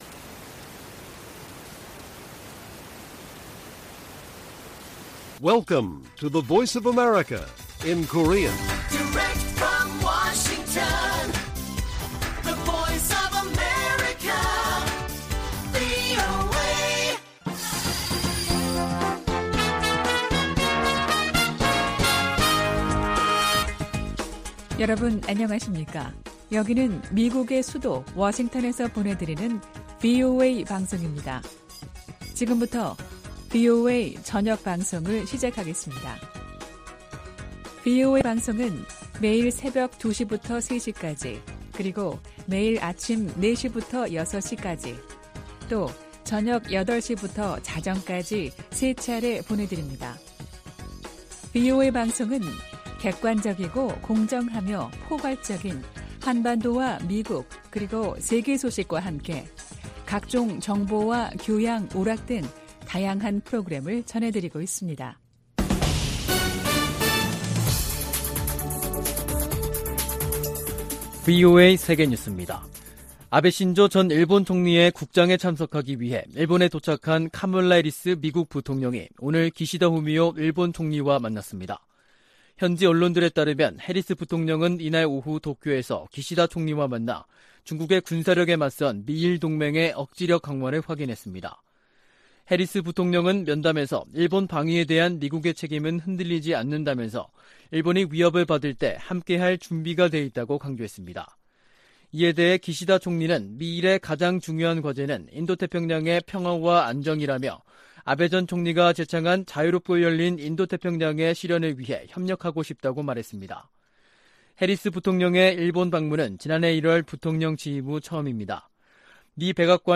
VOA 한국어 간판 뉴스 프로그램 '뉴스 투데이', 2022년 9월 26일 1부 방송입니다. 한국 정부가 북한의 탄도미사일 도발을 규탄했습니다. 미 국무부도 북한 탄도미사일 발사를 비판하며 유엔 안보리 결의 위반임을 강조했습니다. 북한 신의주와 중국 단둥을 오가는 화물열차가 150일 만에 운행을 재개했습니다.